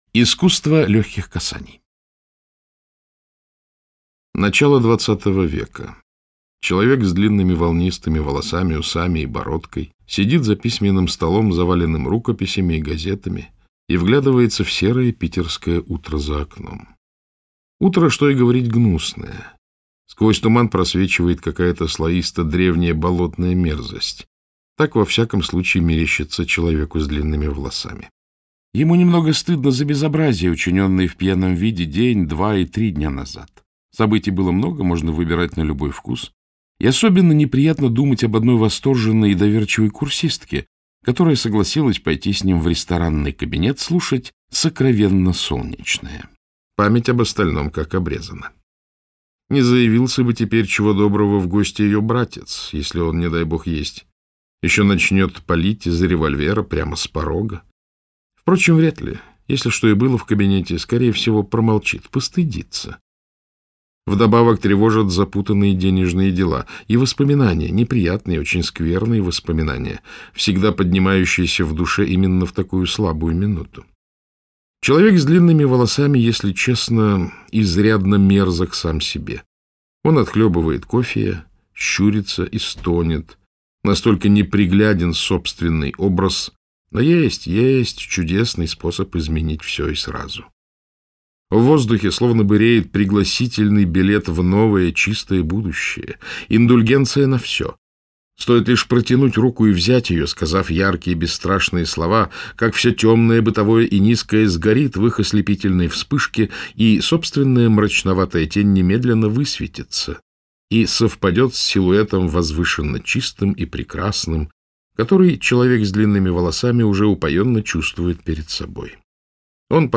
Аудиокнига Искусство легких касаний - купить, скачать и слушать онлайн | КнигоПоиск